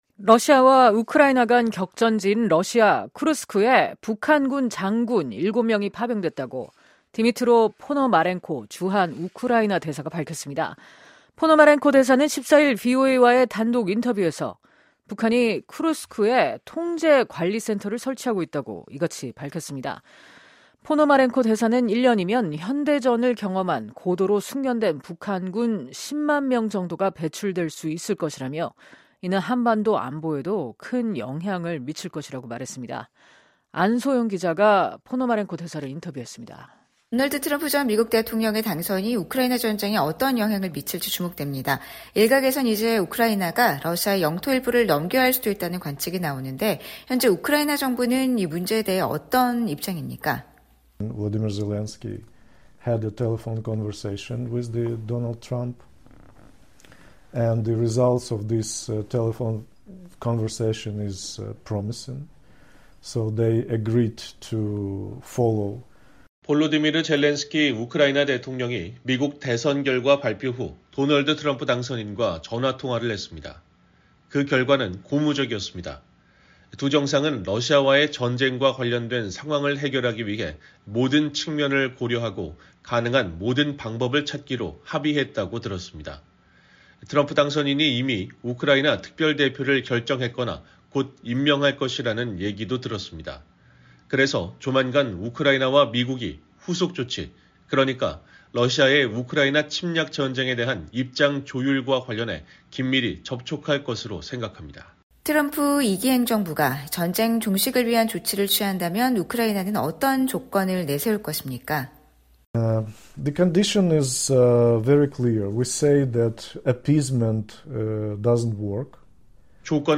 러시아와 우크라이나 간 격전지인 러시아 쿠르스크에 북한군 장군 7명이 파병됐다고 디미트로 포노마렌코 주한 우크라이나 대사가 밝혔습니다. 포노마렌코 대사는 14일 VOA와의 단독 인터뷰에서 북한이 쿠르스크에 통제 관리 센터를 설치하고 있다고 이같이 말했습니다.